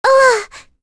May-Vox_Damage_02.wav